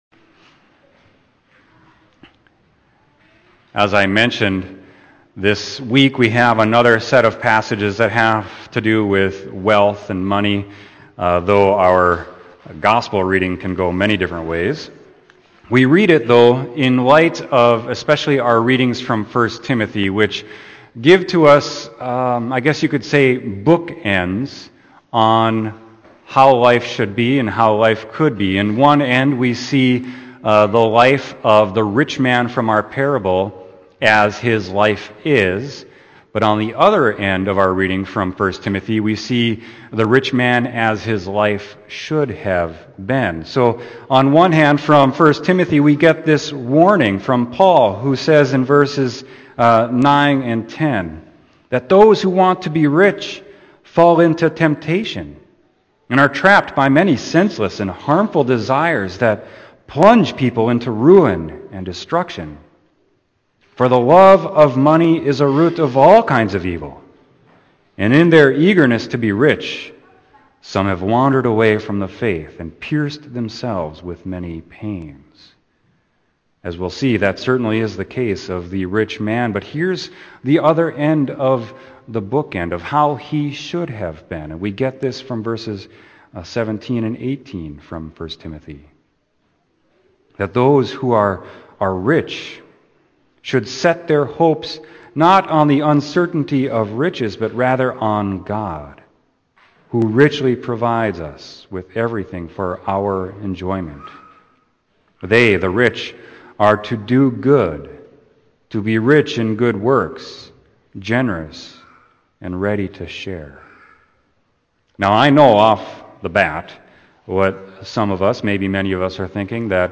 Sermon: Luke 16:19-31